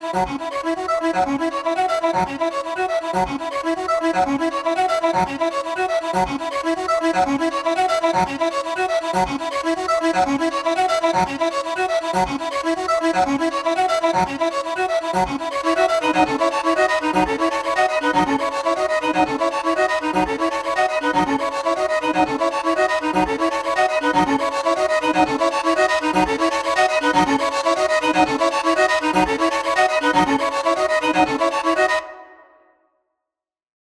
There are lovely scratchy bowed sounds that percolate with sizzling harmonics.
Below are a few sound examples recorded without any post-processing effects.
Arpeggiated line with short attack setting
arpeggiated-line.mp3